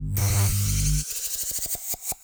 Slash-The-Hordes/222400 - Dynamic Vacuum Suction 01 - Loop v3.ogg at db9a9afa98d3779b045e1559c269c6e8fc33b094